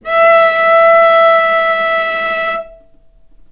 vc-E5-ff.AIF